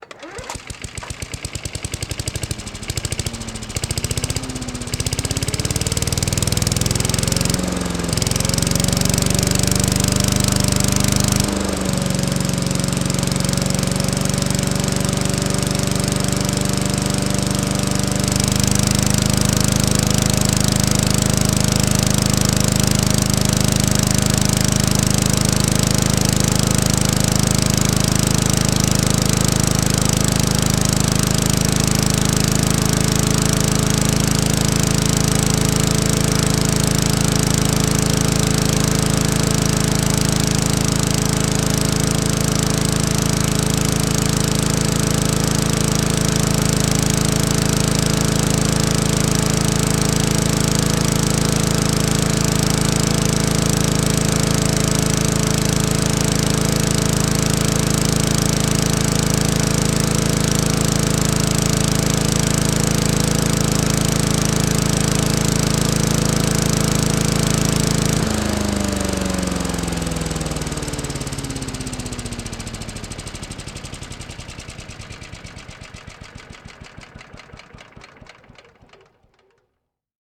Noise from a lawn mower.
lawnmower.mp3